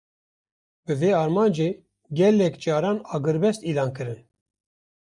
Pronounced as (IPA) /ɑːɡɪɾˈbɛst/